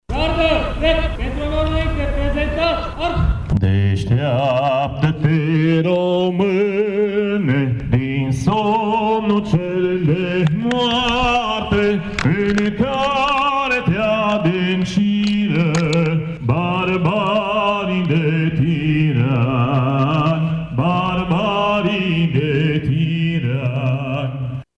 Ceremonia militară și religioasă, de astăzi din Cetatea Medievală, a început cu prezentarea onorului și salutul Drapelului de luptă și al Gărzii de onoare.